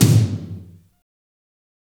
Index of /90_sSampleCDs/Roland - Rhythm Section/KIT_Drum Kits 6/KIT_Combo Kit
TOM BOOSH08R.wav